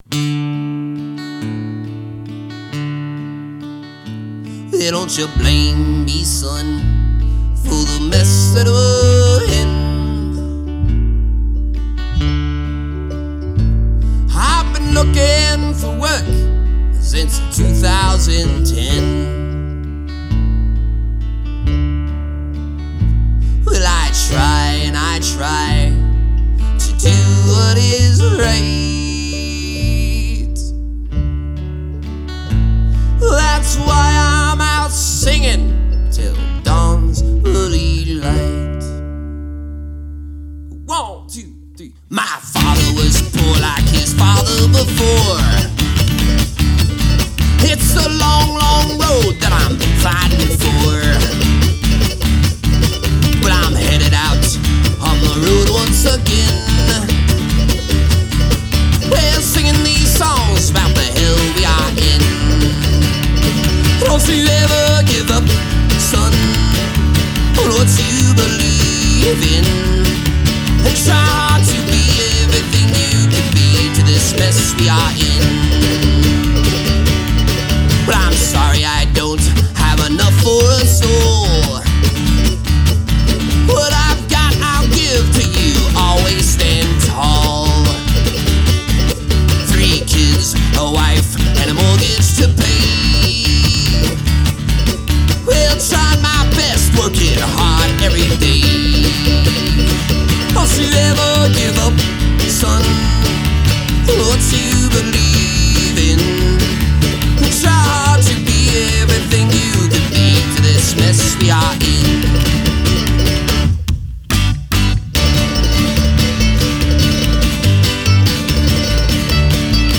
country-folk, révolté